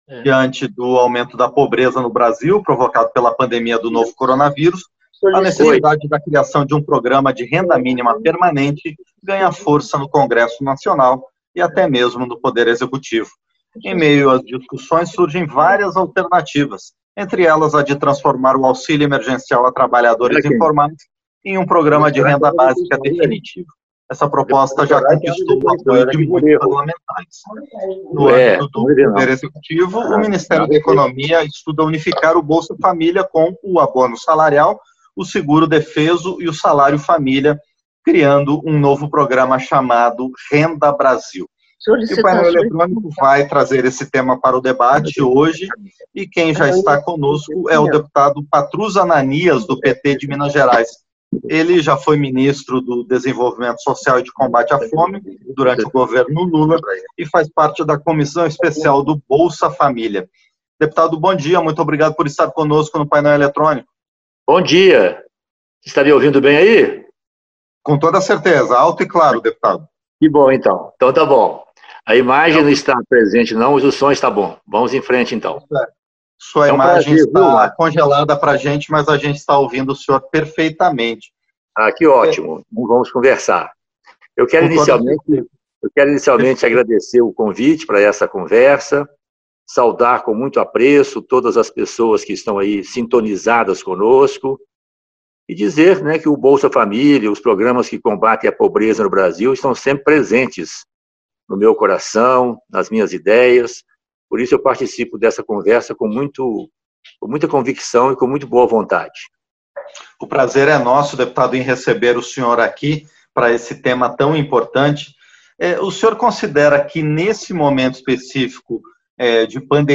Entrevista - Dep. Patrus Ananias (PT-MG)